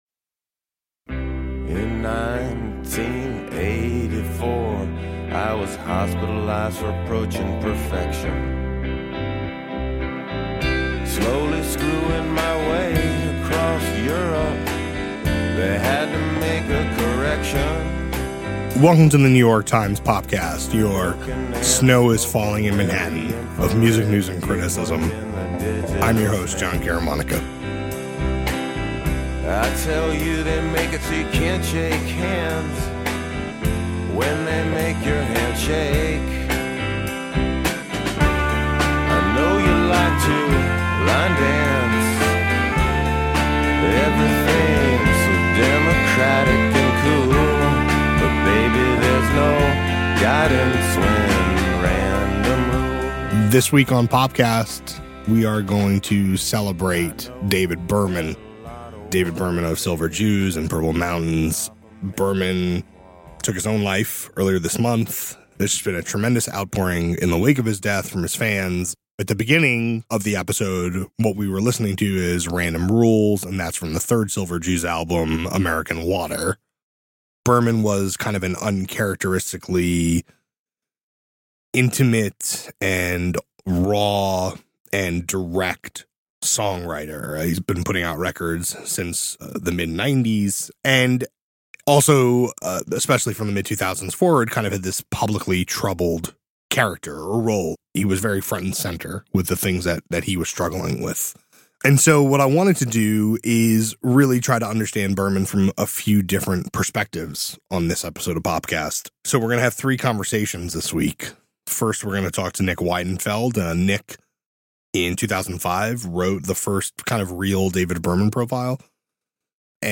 Conversations about his songwriting, his struggles and his striking sweetness outside of music.